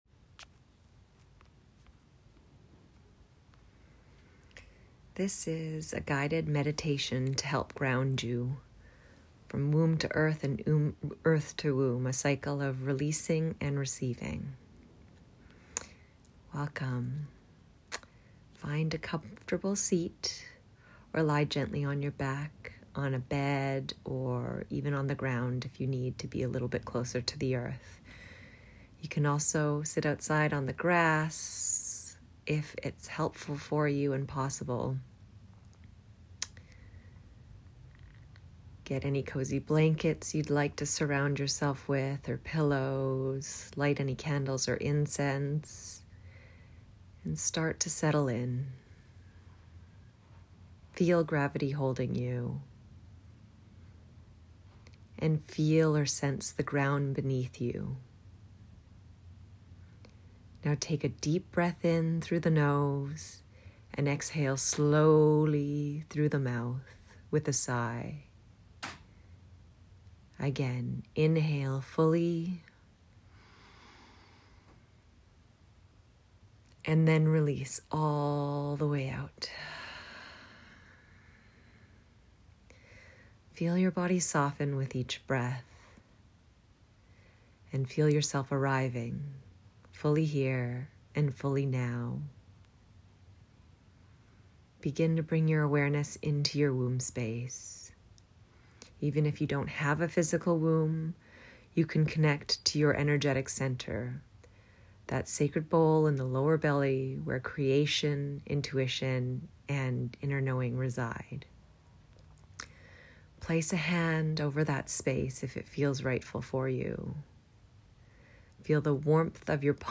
Guided Meditation: Womb to Earth and Earth to Womb, A Cycle of Releasing & Receiving